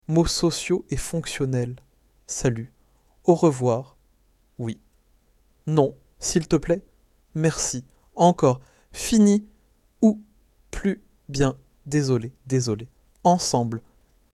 Lesson 8